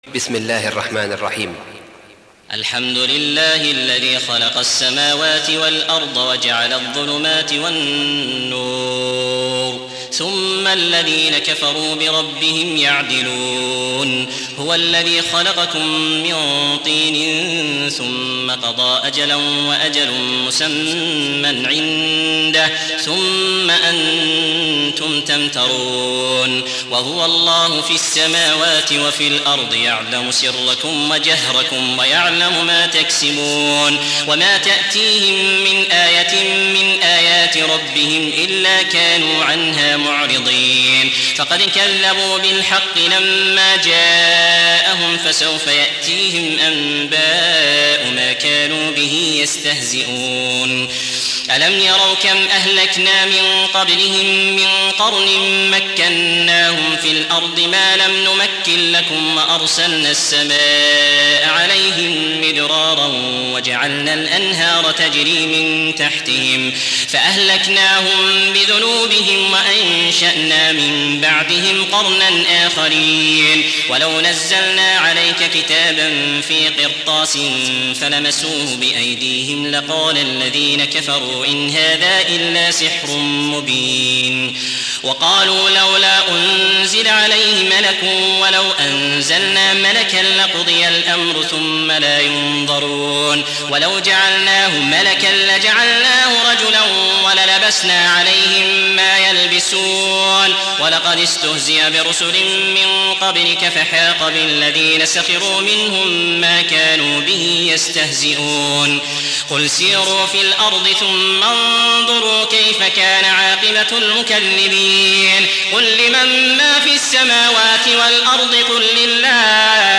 6. Surah Al-An'�m سورة الأنعام Audio Quran Tarteel Recitation
Surah Sequence تتابع السورة Download Surah حمّل السورة Reciting Murattalah Audio for 6.